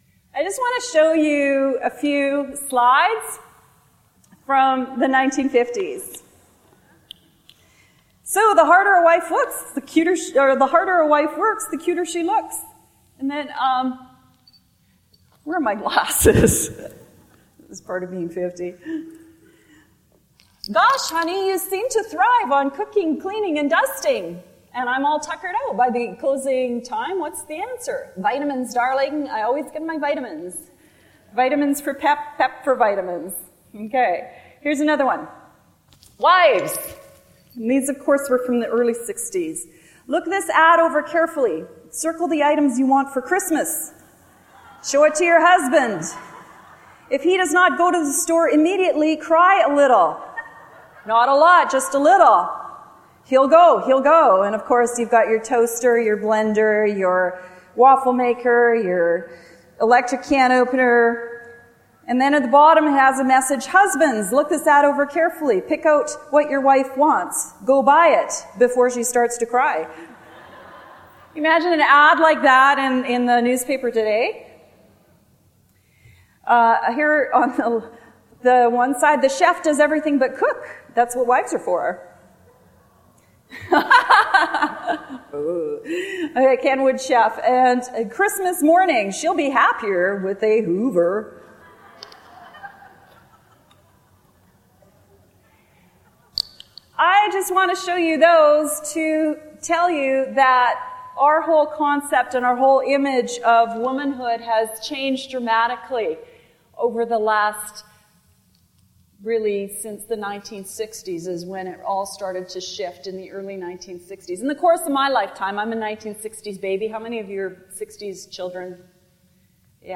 In this workshop, we'll be reviewing the basics: What is true womanhood?